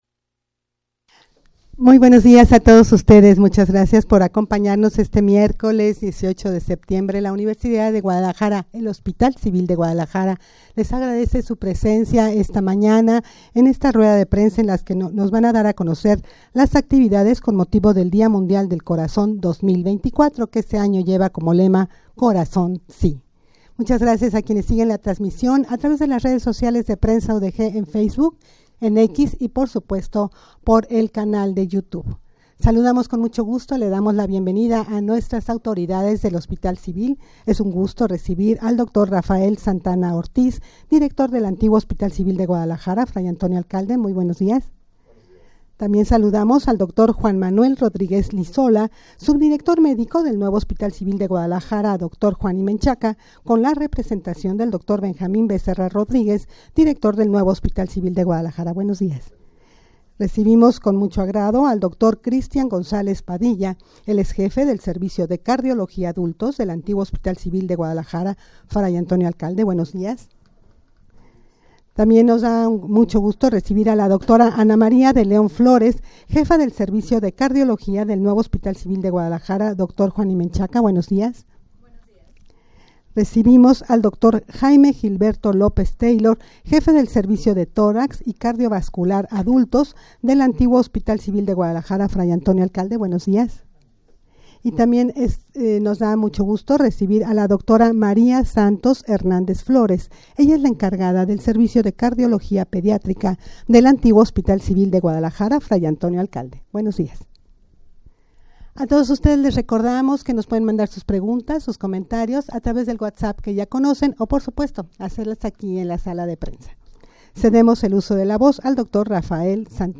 Audio de la Rueda de Prensa
rueda-de-prensa-actividades-hcg-con-motivo-del-dia-mundial-del-corazon-2024.mp3